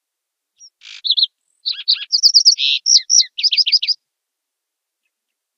Звуки птиц. Sounds of birds.
Звук пения соловья.